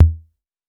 REDD PERC (41).wav